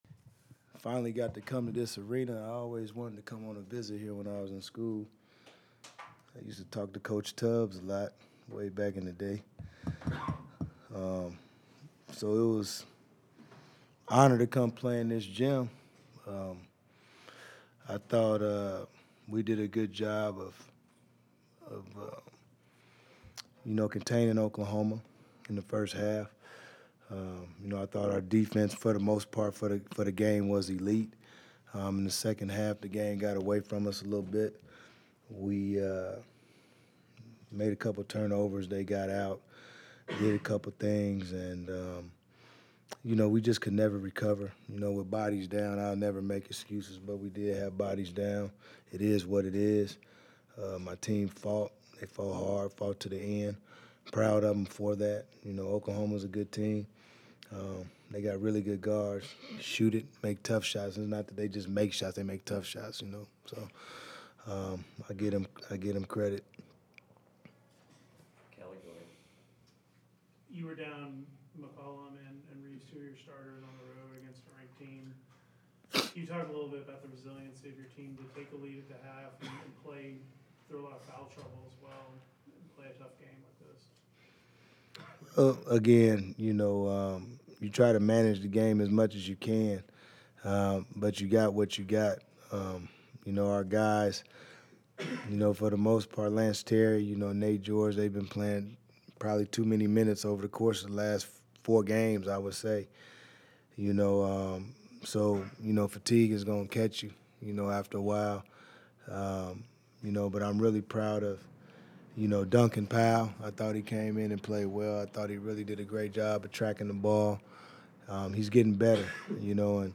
DAMON STOUDAMIRE POST-GAME AUDIO